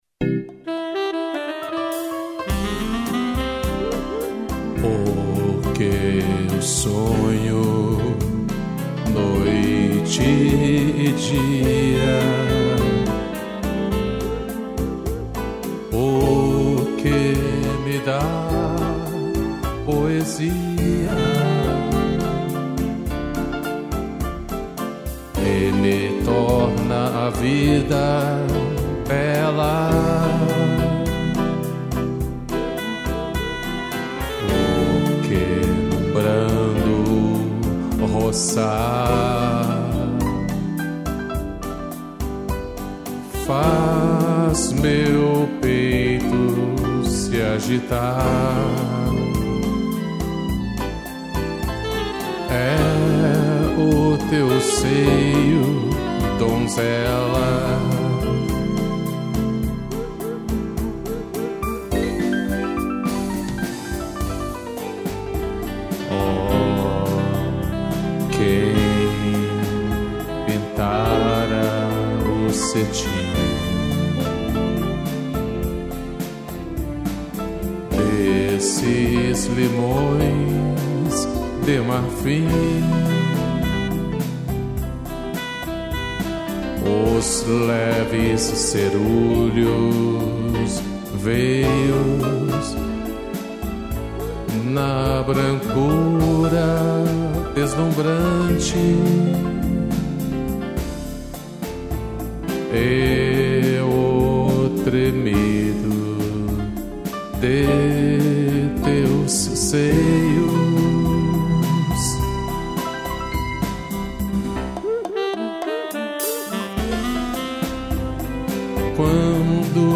piano, sax, strings e cuíca